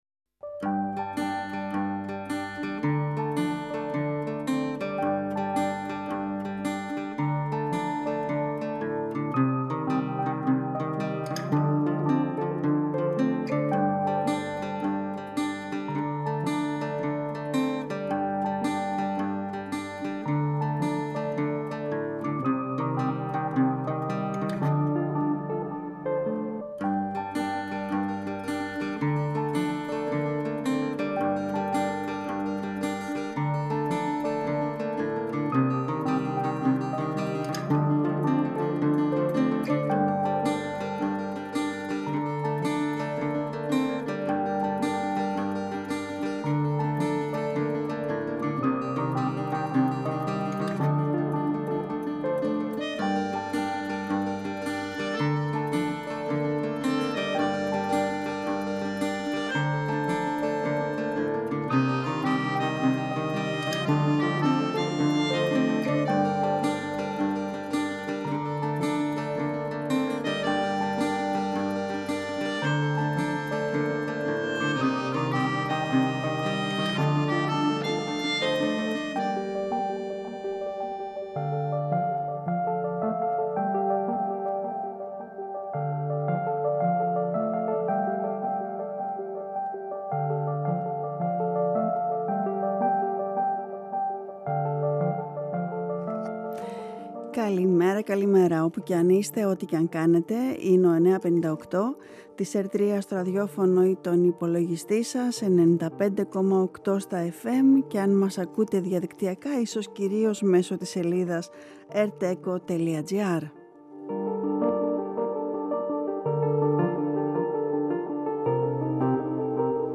Με ένα καινούργιο τραγούδι ξεκινήσαμε την εκπομπή.“Σαν λουλούδι στο βράχο” στίχοι